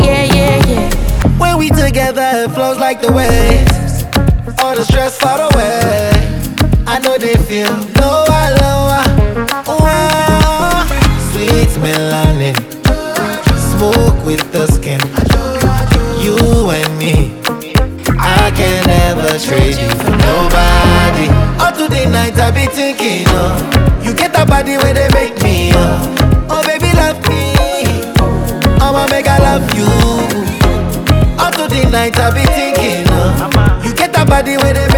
Плавные вокальные партии и чувственный бит
Глубокий соул-вокал и плотные хоровые партии
Жанр: R&B / Соул